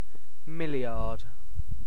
Ääntäminen
Synonyymit billion yard Ääntäminen UK : IPA : [ˈmɪl.jəd] UK : IPA : /ˈmɪlɪɑːd/ US : IPA : /ˈmɪliɑɹd/ Tuntematon aksentti: IPA : /ˈmɪl.jɚd/ Haettu sana löytyi näillä lähdekielillä: englanti Käännöksiä ei löytynyt valitulle kohdekielelle.